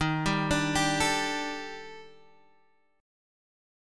D#M7b5 Chord